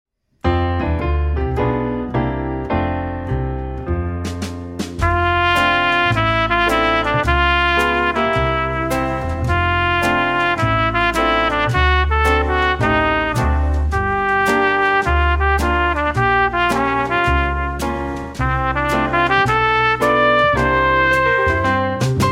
Swingová přednesová skladba pro trubku